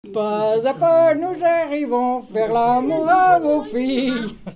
circonstance : conscription
Genre brève
Pièce musicale inédite